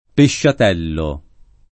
pesciatello [ peššat $ llo ] s. m.